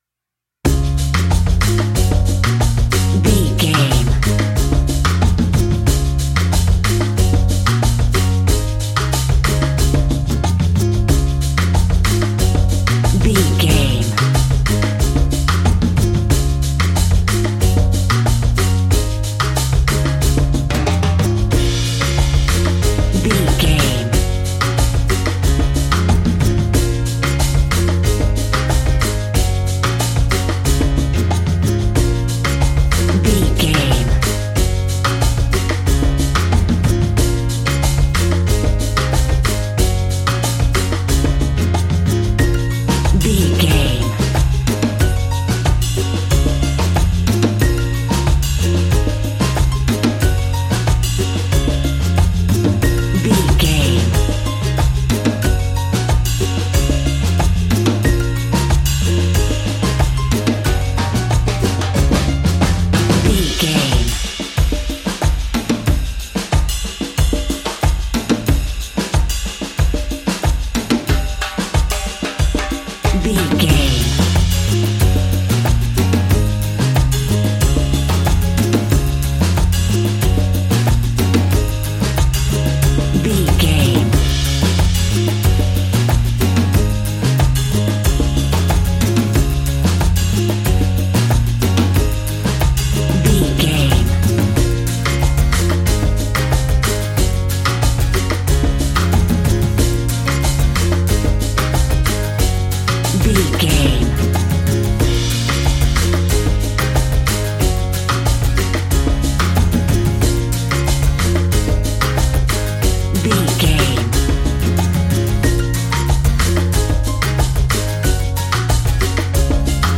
Ionian/Major
D♭
cheerful/happy
mellow
drums
electric guitar
percussion
horns
electric organ